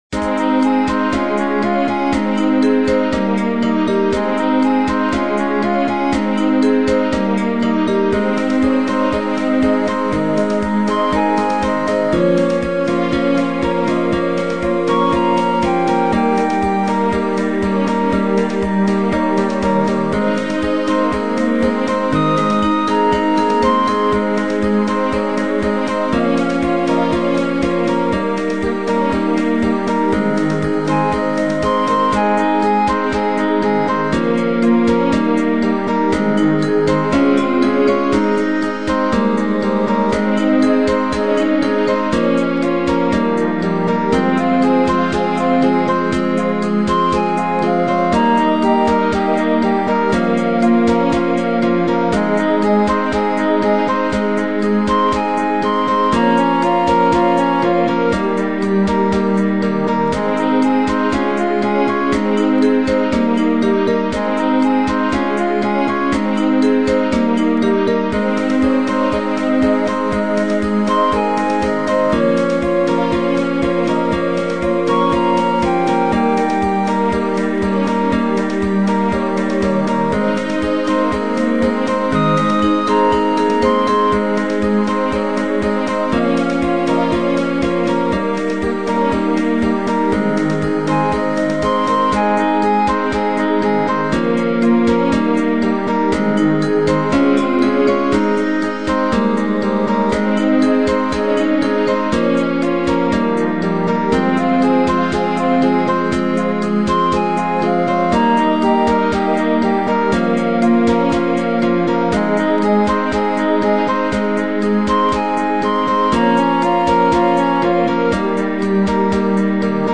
A hangminőség hagy kívánnivalót maga után, csak a nagyon kíváncsiak és nagyon bátrak hallgassák meg.
nagyzenekarra (2302 kb, 3'19") is,
a hatvanas évek táncdalfesztiváljainak stílusában: